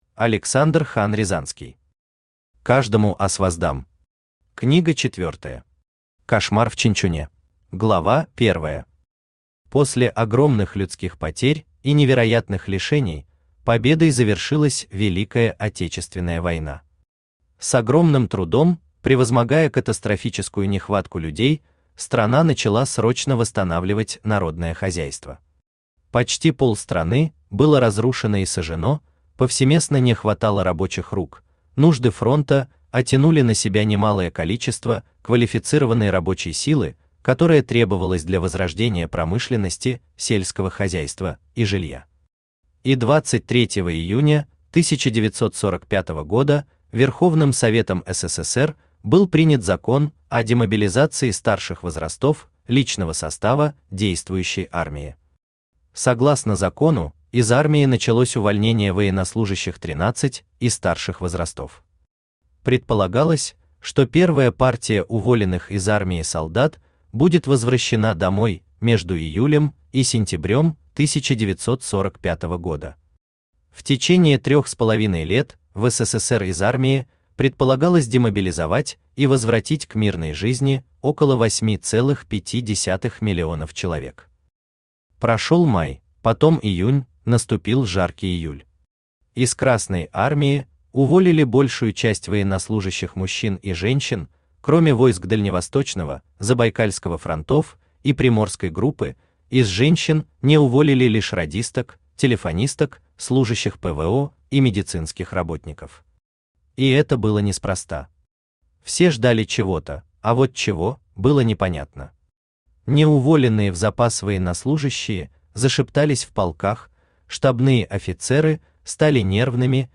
Аудиокнига Каждому аз воздам! Книга четвёртая. Кошмар в Чанчуне.
Автор Александр Хан-Рязанский Читает аудиокнигу Авточтец ЛитРес.